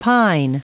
Prononciation du mot pine en anglais (fichier audio)
Prononciation du mot : pine